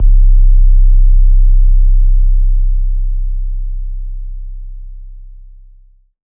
808s